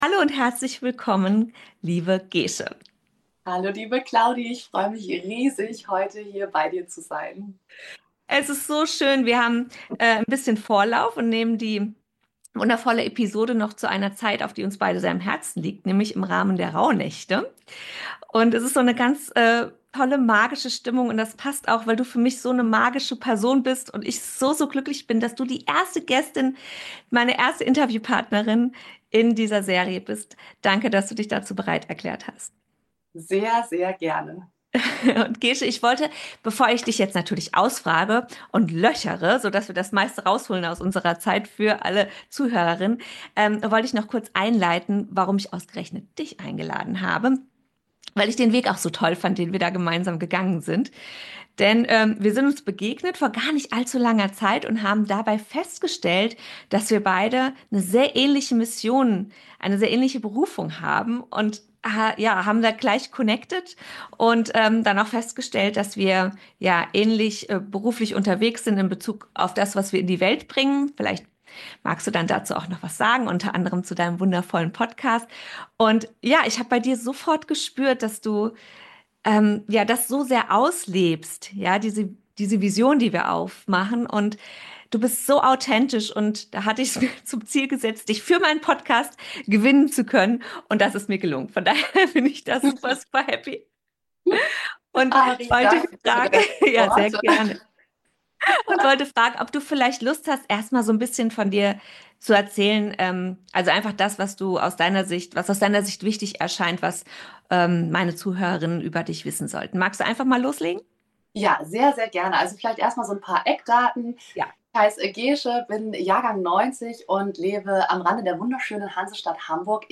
In diesem Interview teilt sie ihre wichtigsten Erkenntnisse darüber, wie du dein tägliches Glück im Alltag findest – und dein Leben nach deinen eigenen Regeln gestaltest.